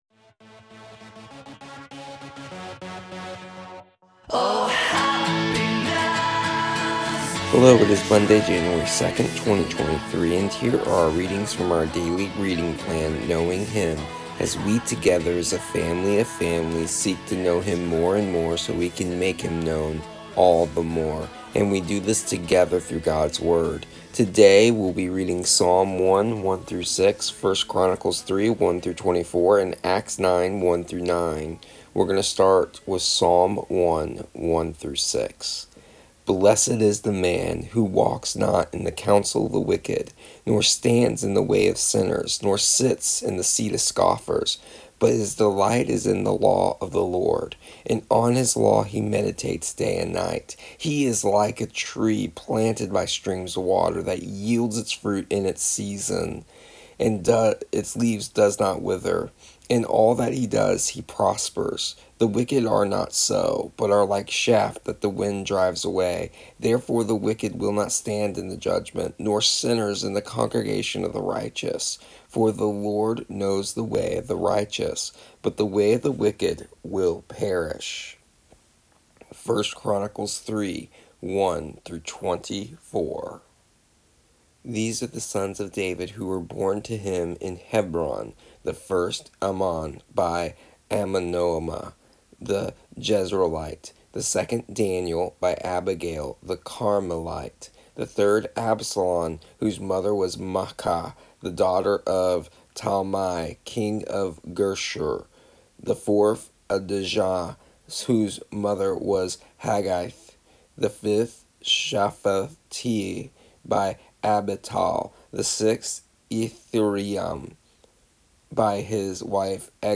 Here is the audio version of our daily readings from our daily reading plan Knowing Him for January 2nd, 2023.